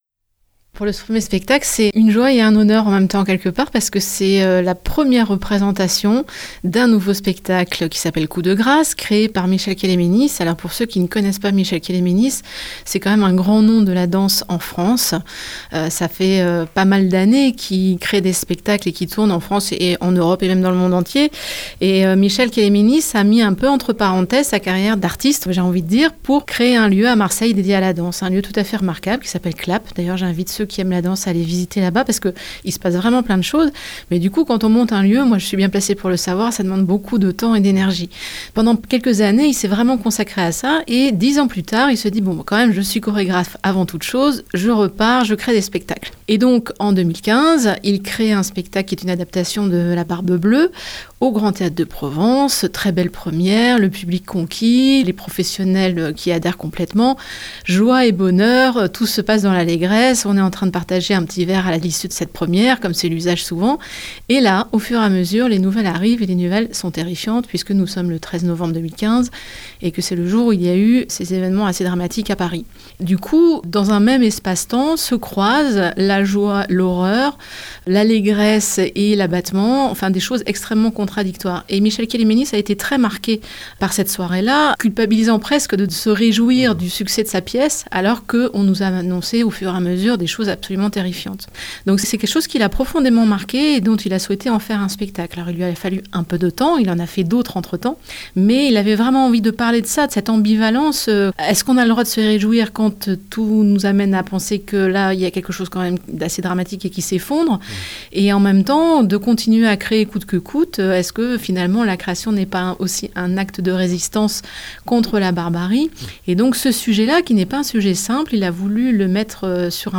présente le spectacle au micro